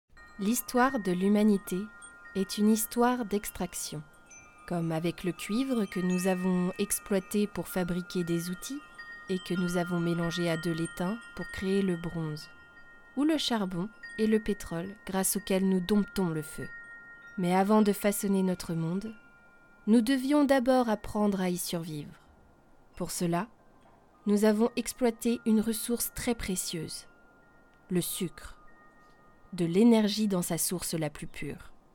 Voix Documentaire